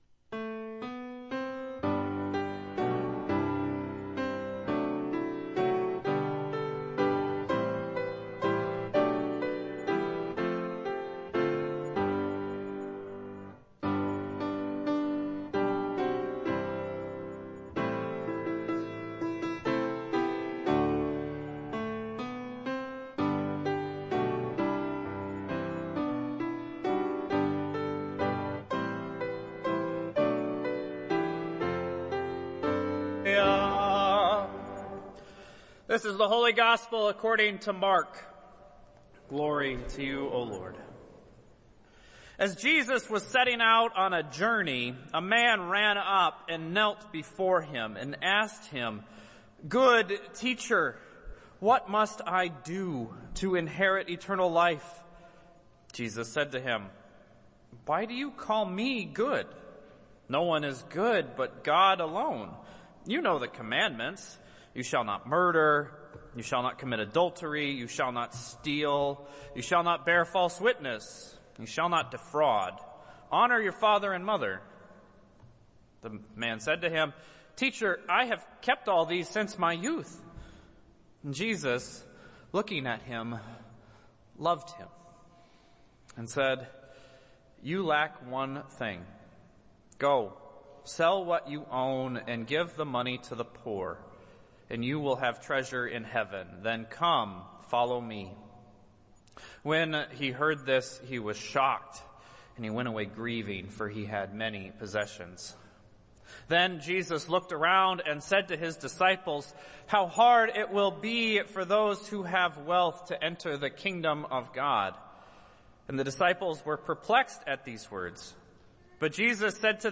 Worship Services | Christ The King Lutheran Church
Sermon Notes